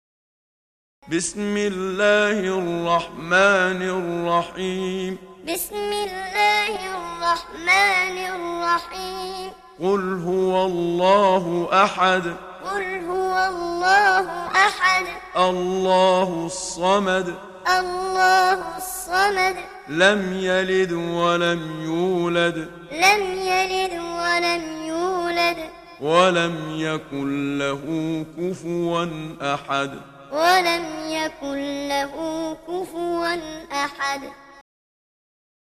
Muallim